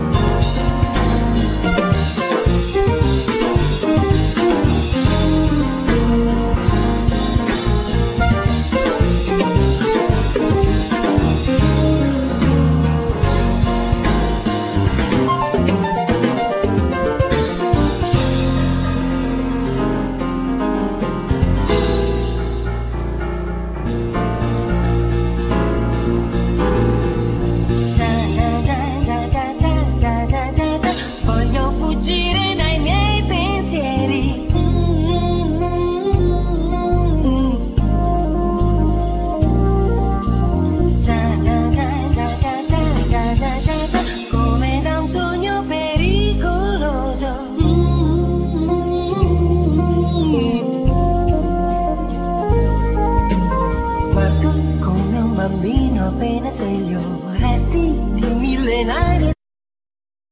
Piano,Keyboards
Vocals
El. bass,Double bass
El. + Ac.guiatrs
Drums